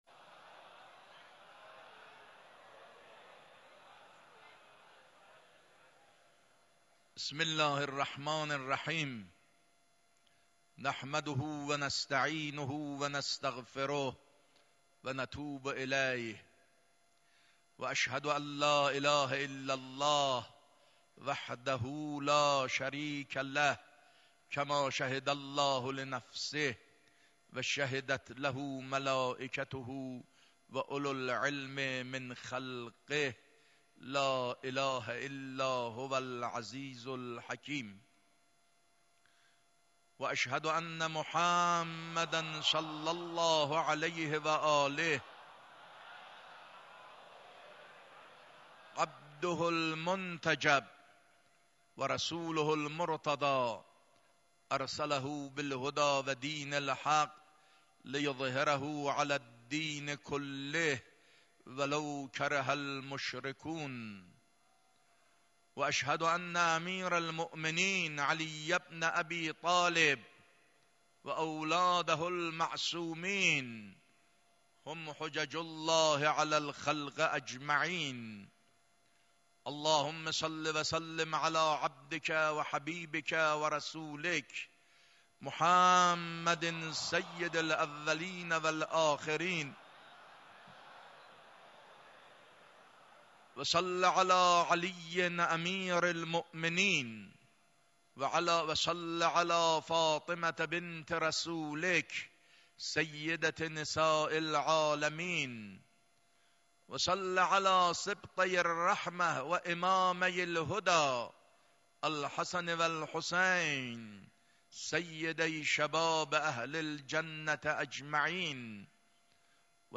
نماز جمعه خطبه دوم 5.2.93.mp3
نماز-جمعه-خطبه-دوم-5.2.93.mp3